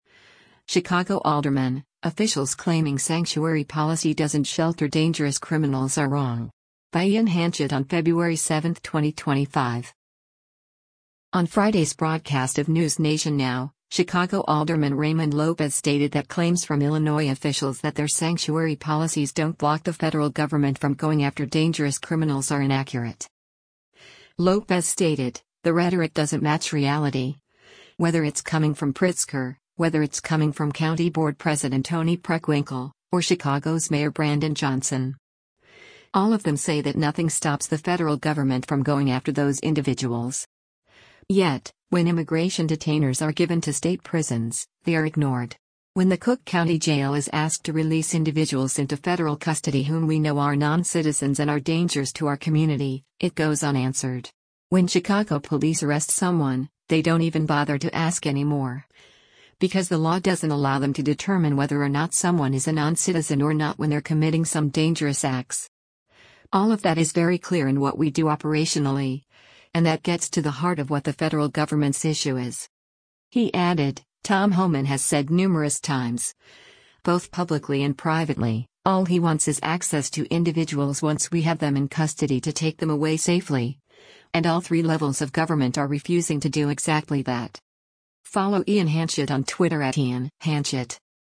On Friday’s broadcast of “NewsNation Now,” Chicago Alderman Raymond Lopez stated that claims from Illinois officials that their sanctuary policies don’t block the federal government from going after dangerous criminals are inaccurate.